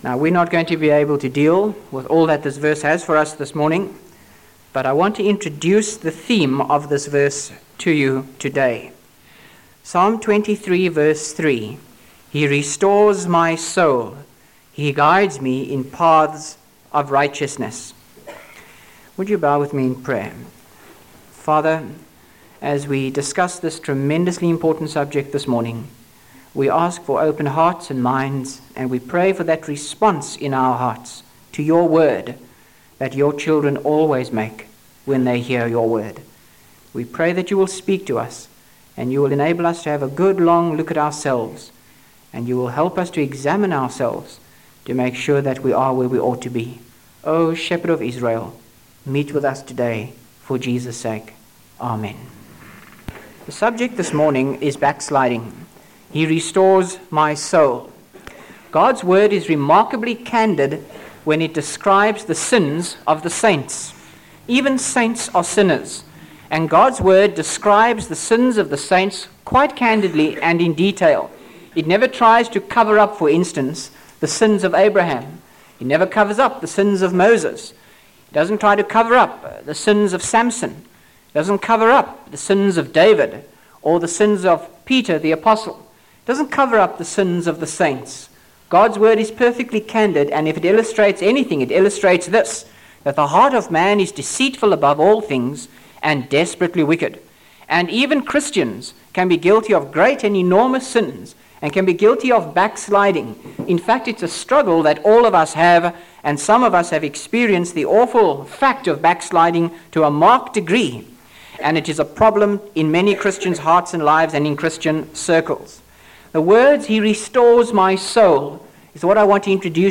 by Frank Retief | Feb 3, 2025 | Frank's Sermons (St James) | 0 comments